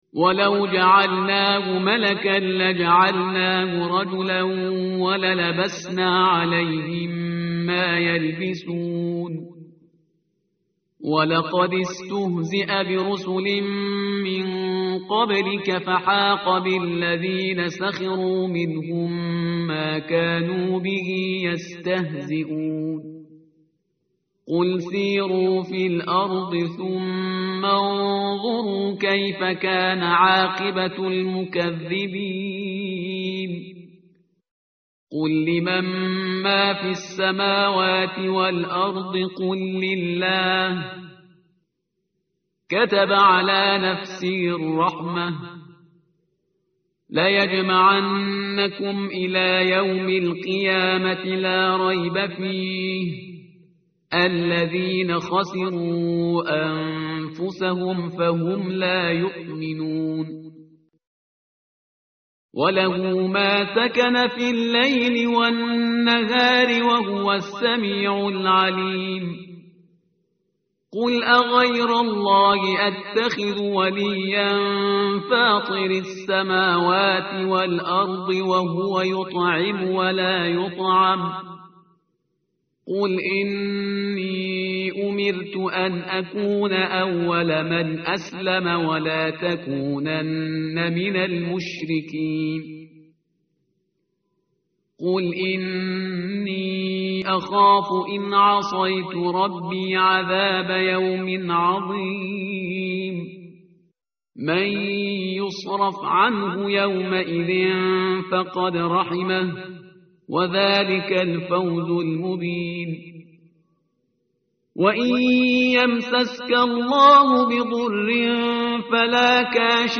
متن قرآن همراه باتلاوت قرآن و ترجمه
tartil_parhizgar_page_129.mp3